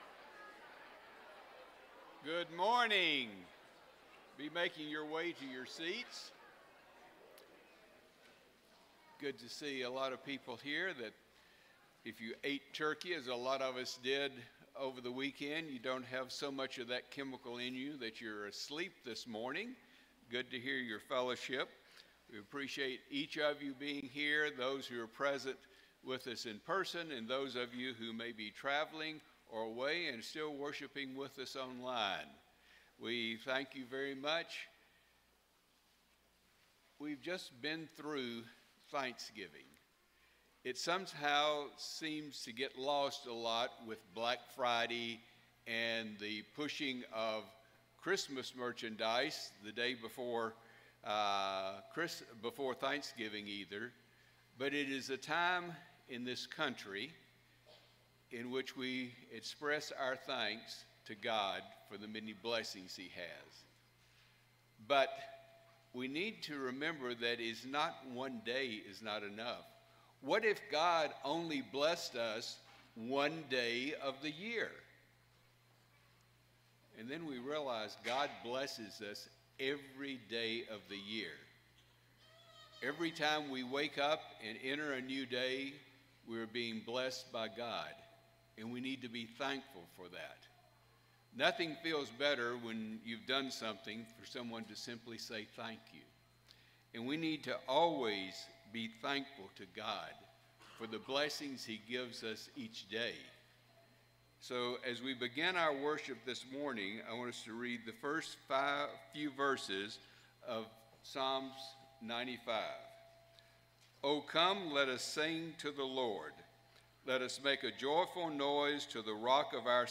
Colossians 1:13, English Standard Version Series: Sunday AM Service